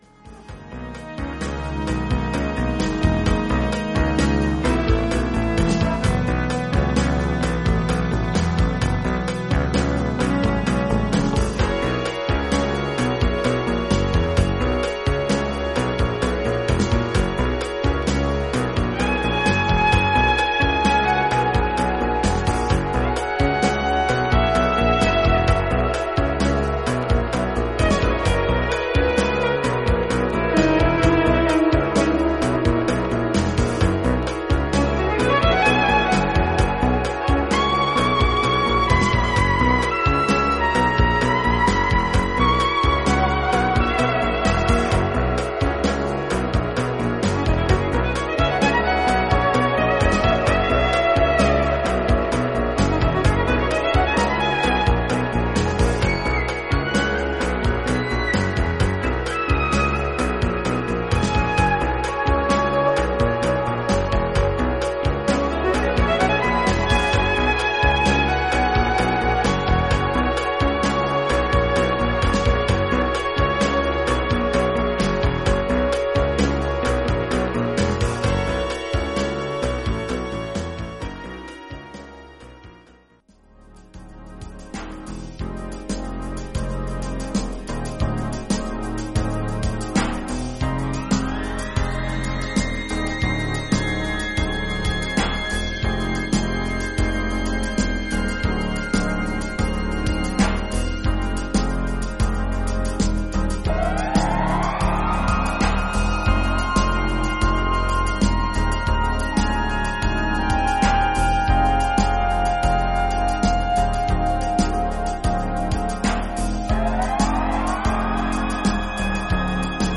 リズム・マシンで打ち込まれたビートにシンセのアルペジオ